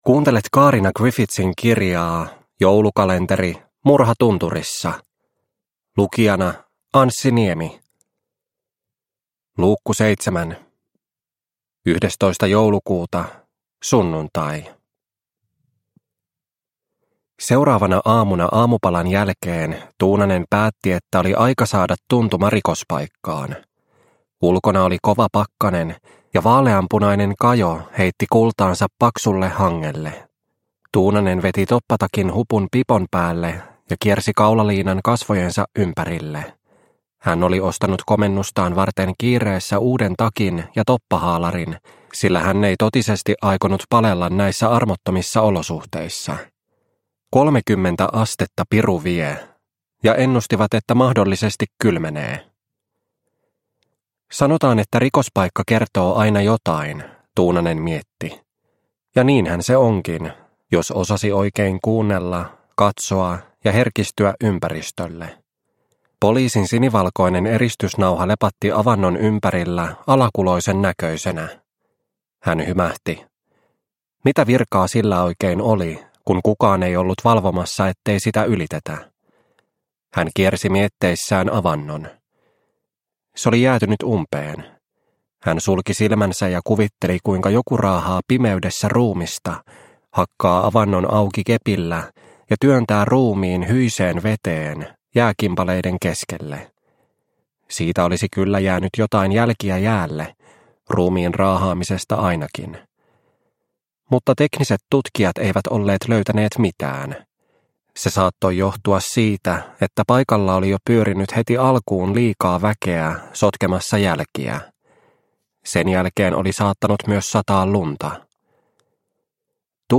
Murha tunturissa - Osa 7 – Ljudbok – Laddas ner